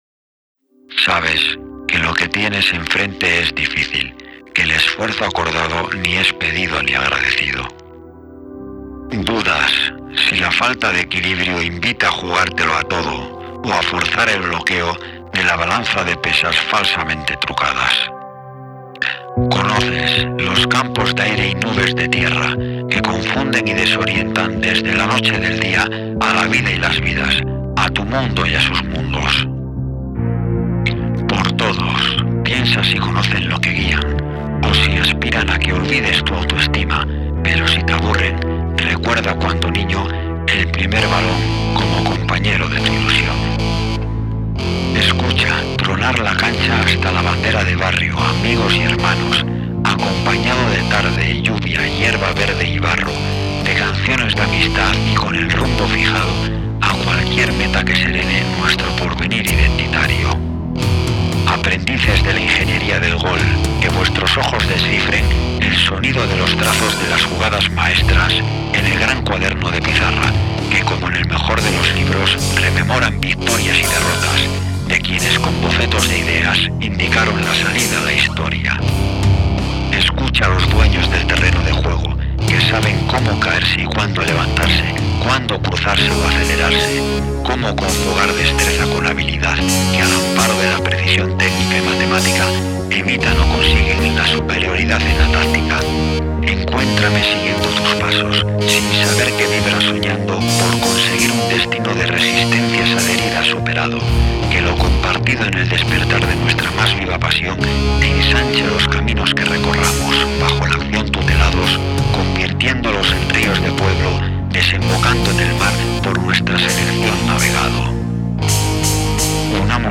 Canción amateur hecha con DAW